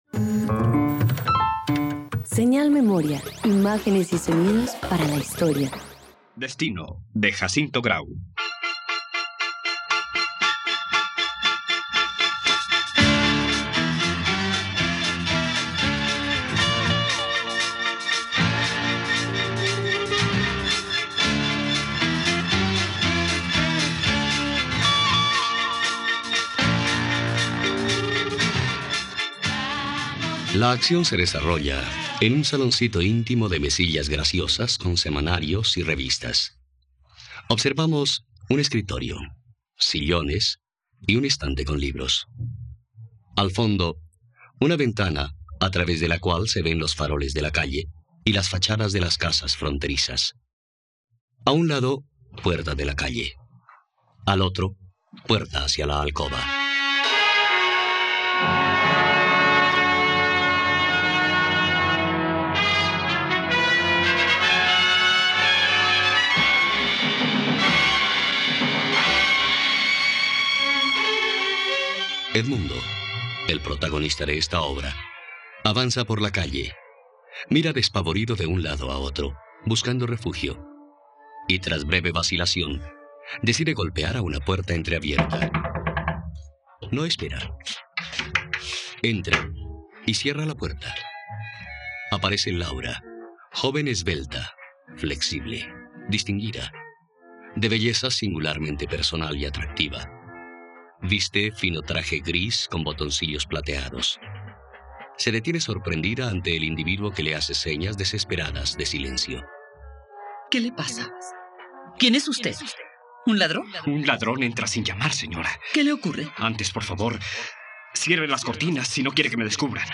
Destino - Radioteatro dominical | RTVCPlay